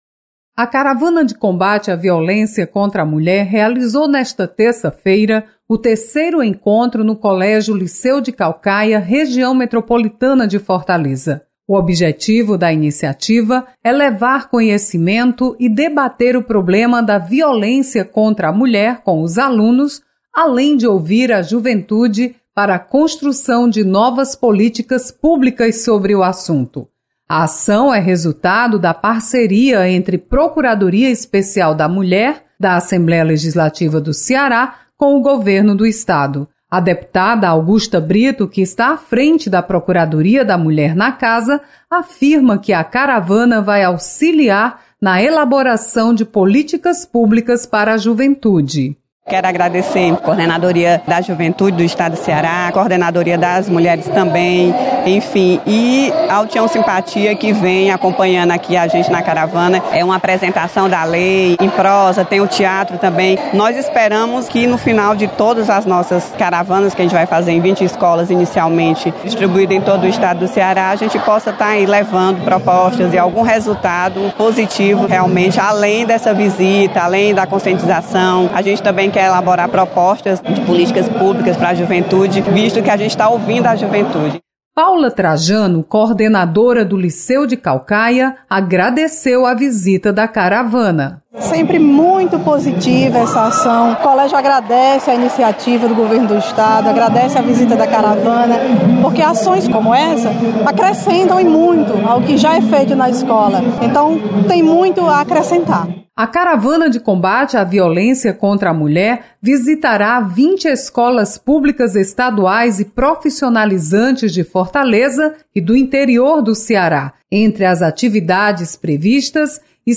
Você está aqui: Início Comunicação Rádio FM Assembleia Notícias Caravana